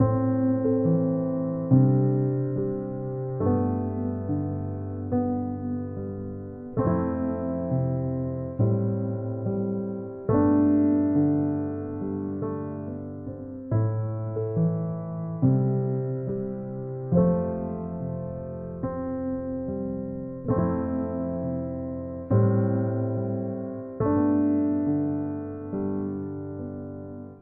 描述：寒冷的缓慢的70bpm的钟声像钢琴独奏的音符。
标签： 70 bpm Chill Out Loops Piano Loops 4.61 MB wav Key : D
声道立体声